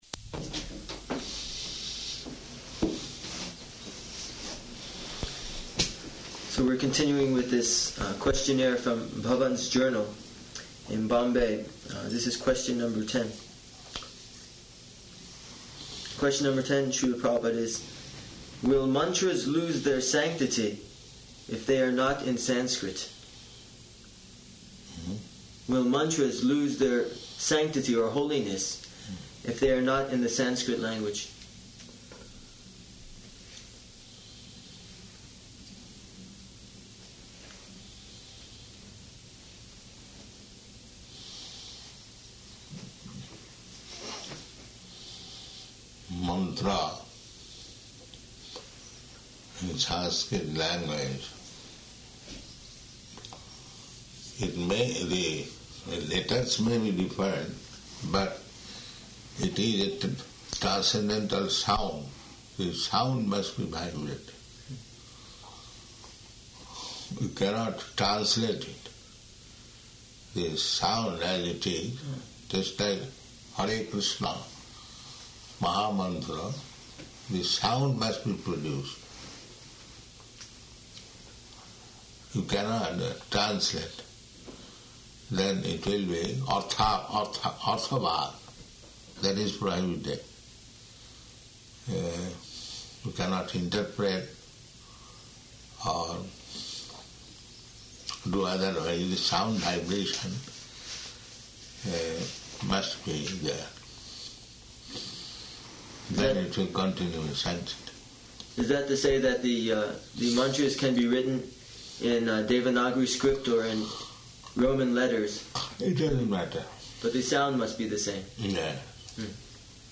Type: Lectures and Addresses
Location: New Vrindavan